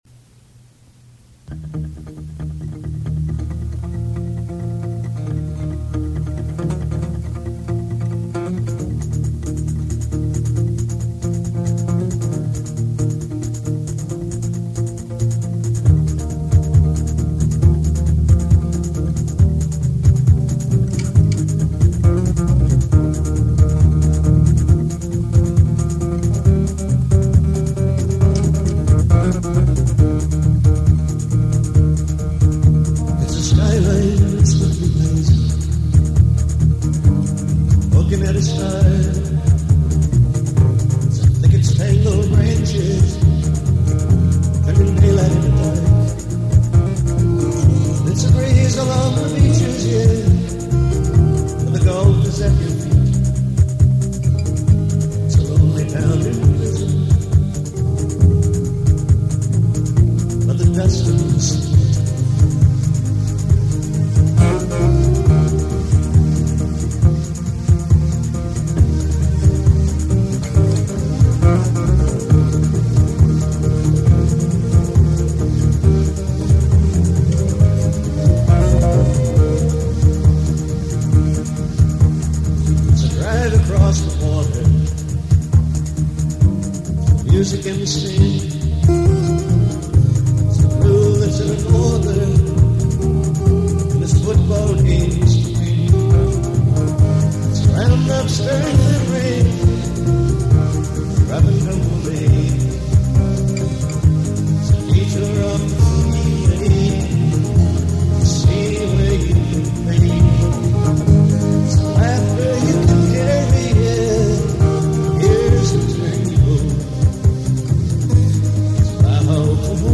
Great guitar and bass and a driving melody.
However, the energy on these versions is outstanding.